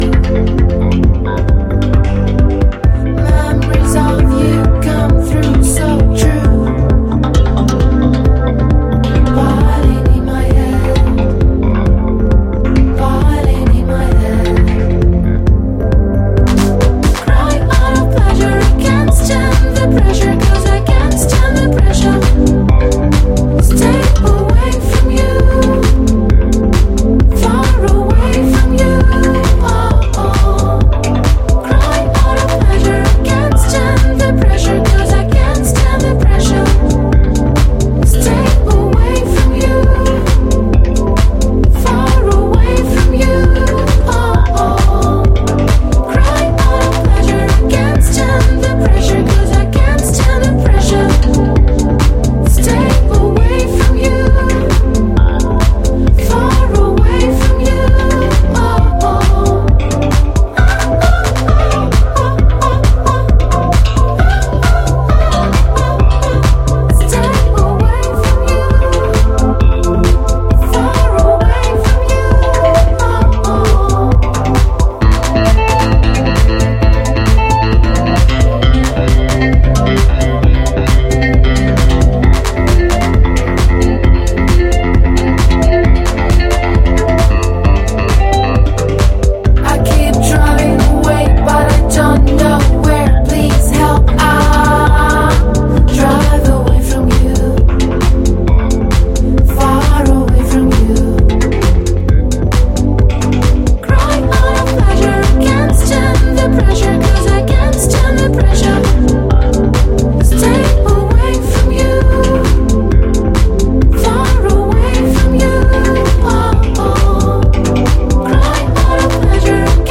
inspired by 70-80s music
the song is a melancholic, pop-leaning anthem